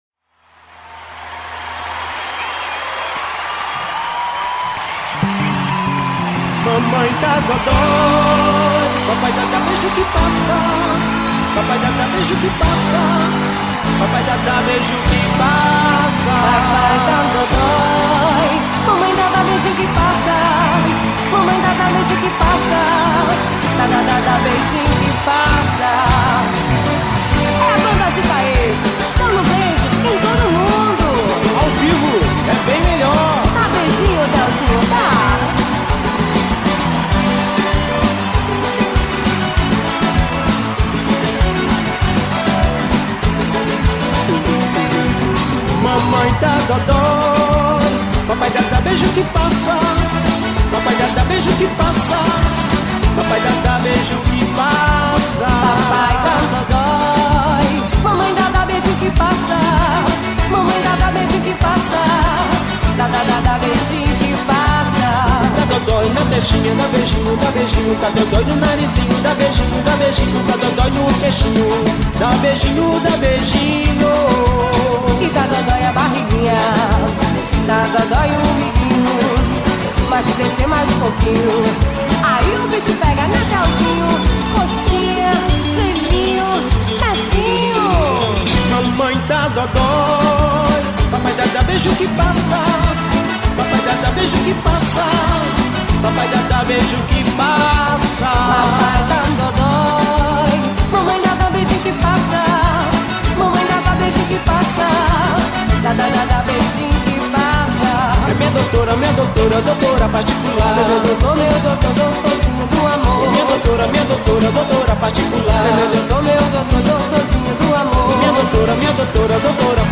Versão ao vivo